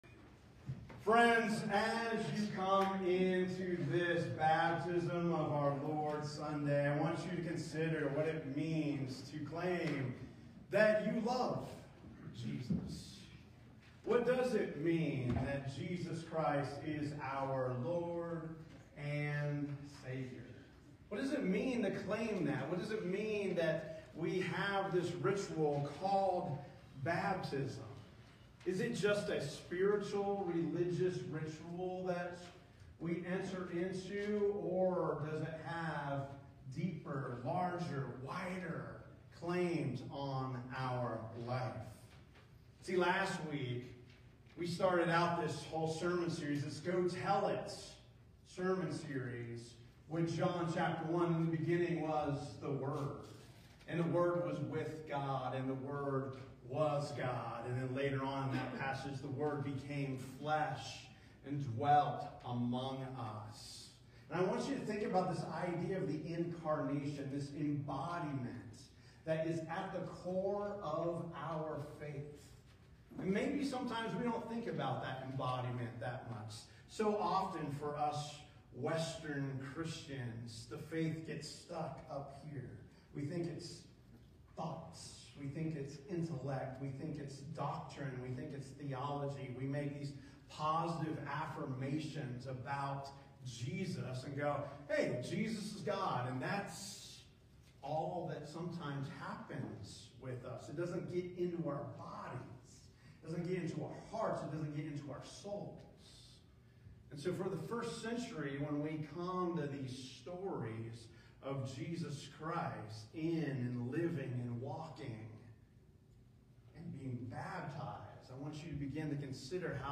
1/11/26 Sermon: Go Tell It from the Water